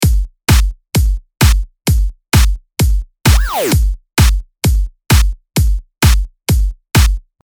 DTM講座　Electro House （エレクトロ ハウス）の作り方④